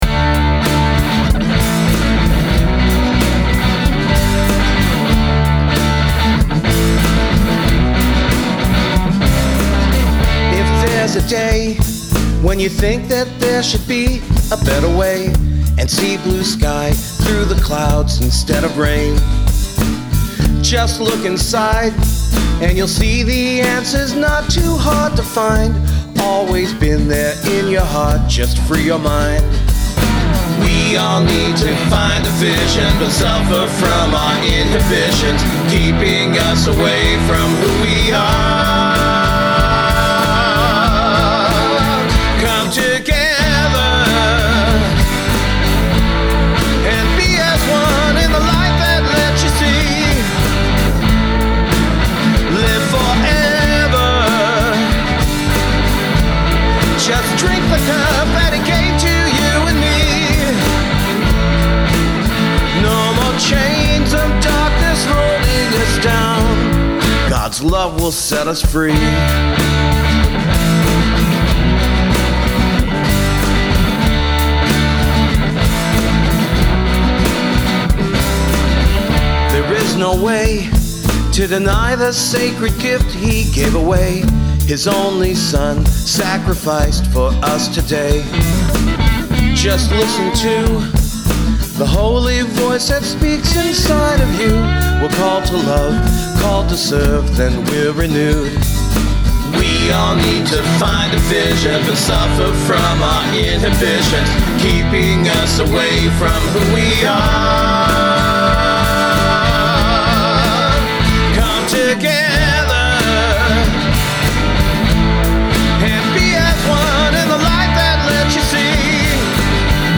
I’ve since changed the name to “God’s Love Will Set Us Free” but what I failed to mention was that the electric guitar parts were recorded, close-miked with the volume level being normal conversation level!
The electric guitars haven’t been tweaked except for adding just a touch more highs in the EQ (the original tone was fine, but I wanted the guitars to cut through the mix a bit better because there was lots of overdrive):
What great quality at normal conversation levels!